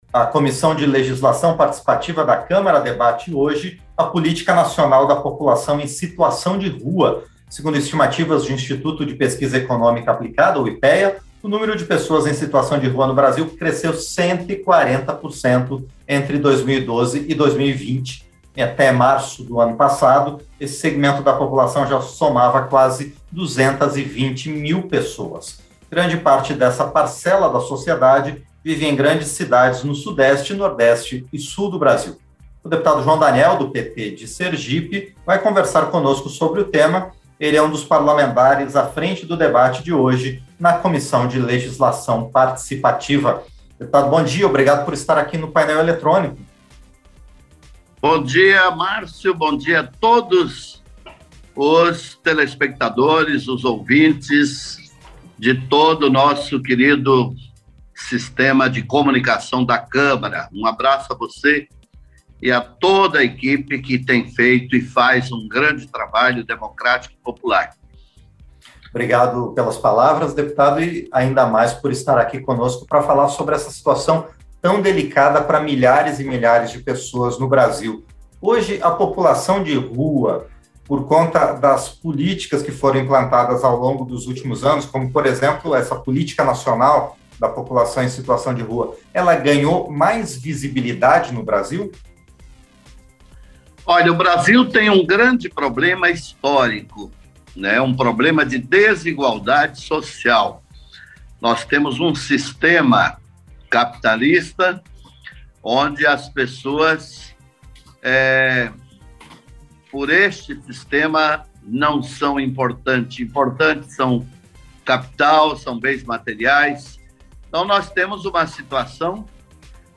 Entrevista - Dep. João Daniel (PT-SE)